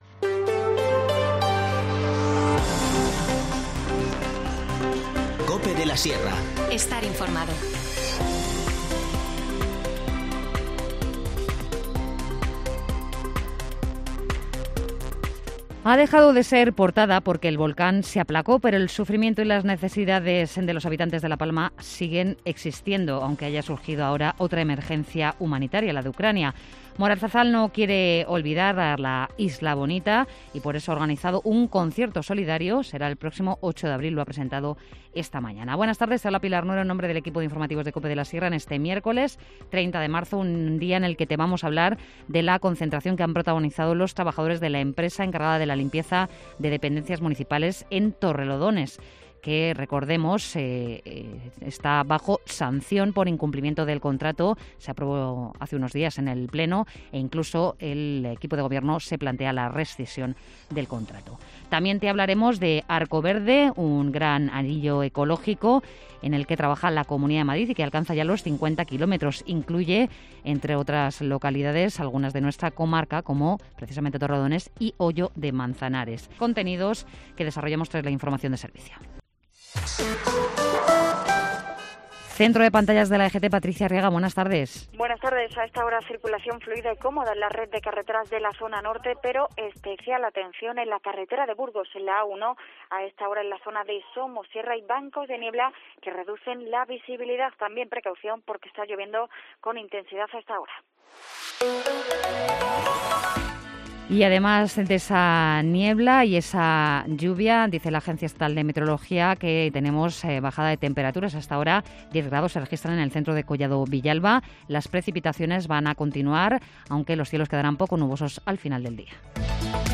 Informativo Mediodía 30 marzo